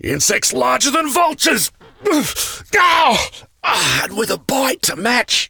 insect1.ogg